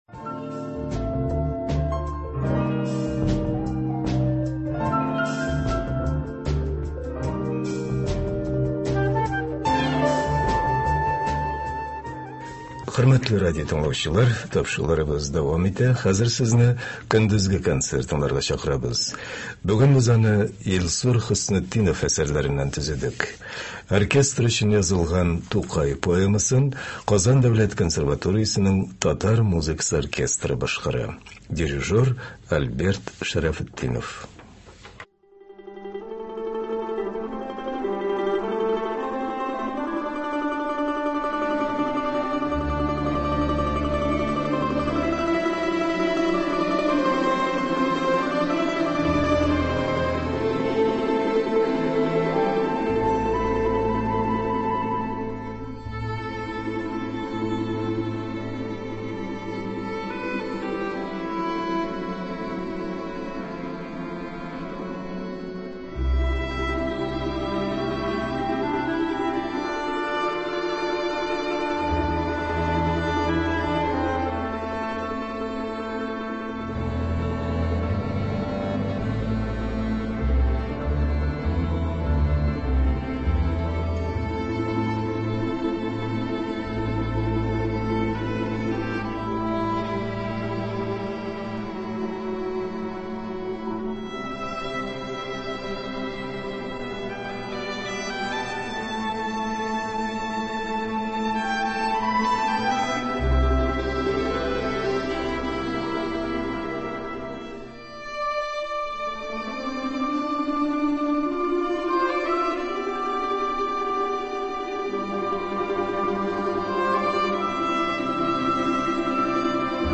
Кичке концерт.